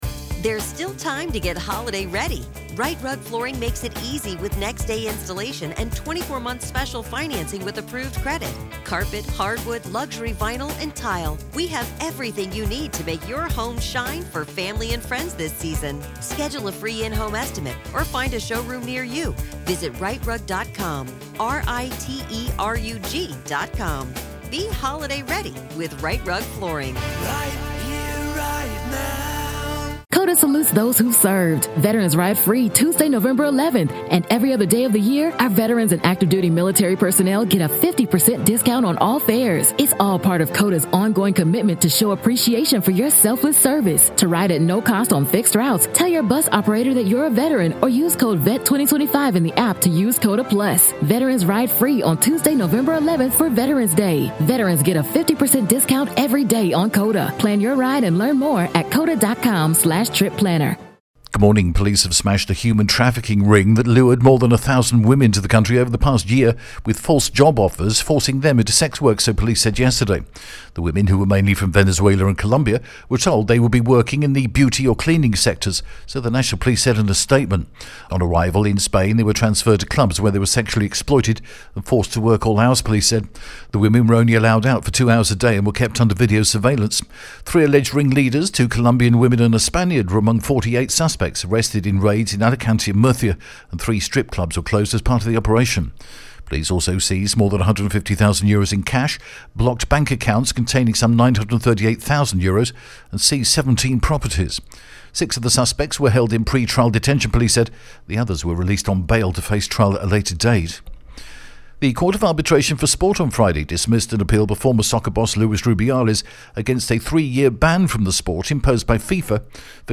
The latest Spanish news headlines in English: February 24th 2025